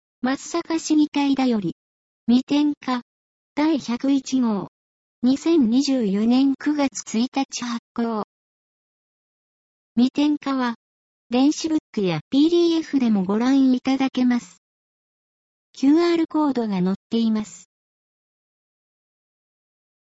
なお、この音声は「音訳グループまつさか＜外部リンク＞」の皆さんの協力で作成しています。